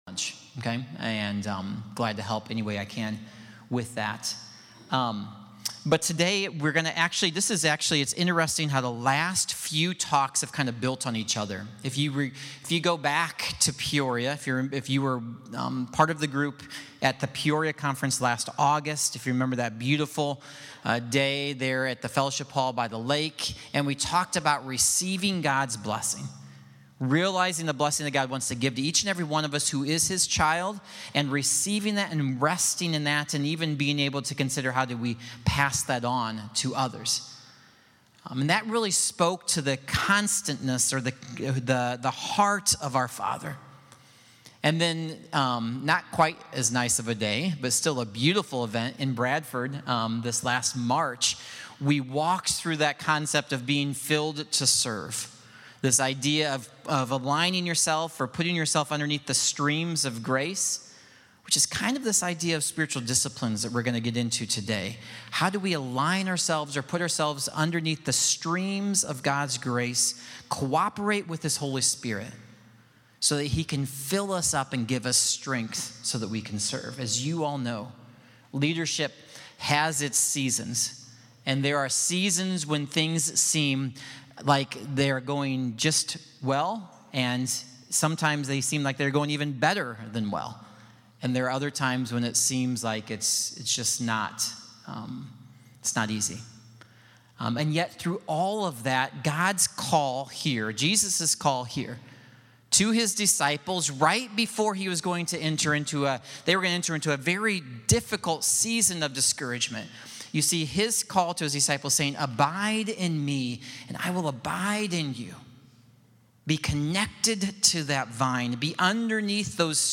Listed below are presentations given by ACCFS staff to elders and/or their wives.